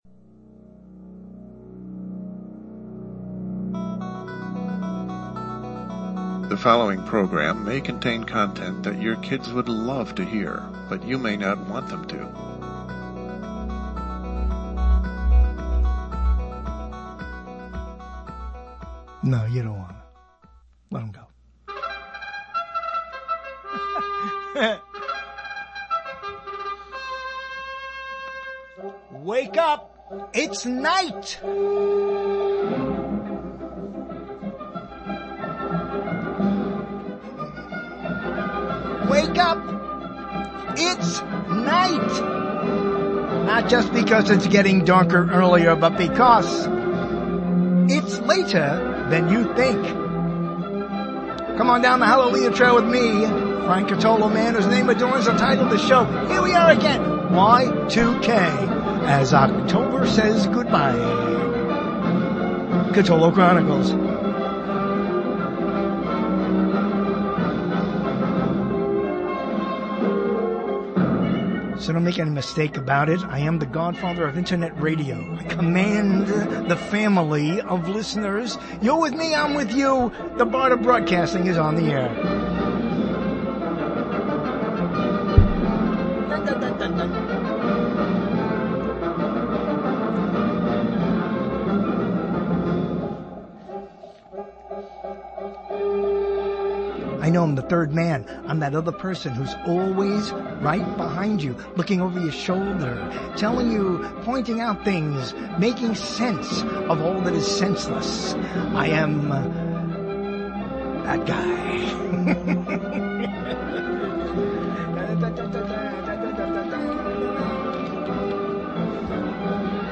broadcast
live , radio